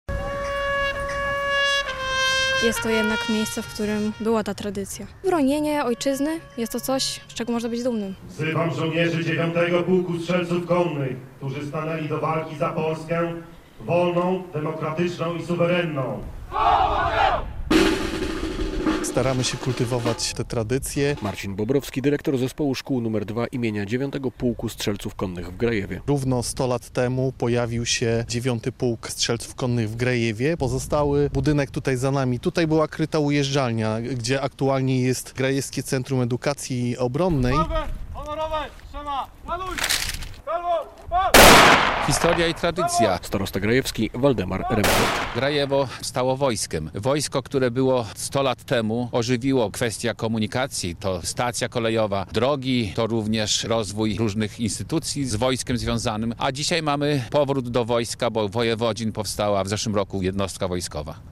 Uroczystości odbyły się w piątek (18.10) w Zespole Szkół nr 2 im. 9. Pułku Strzelców Konnych w Grajewie.